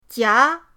jia2.mp3